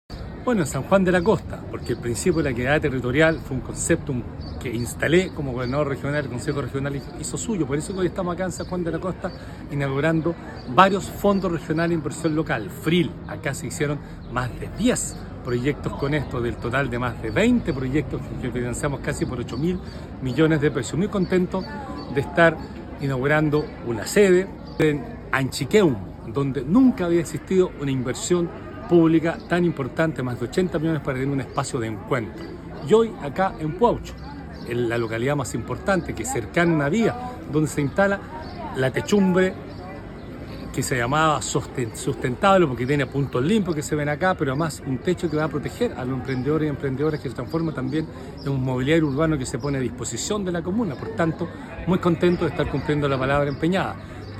El Gobernador de la región de Los Lagos, Patricio Vallespin, destacó la equidad territorial que permitió que San Juan de la Costa pueda inaugurar dos proyectos que mejoraran la calidad de vida de los vecinos.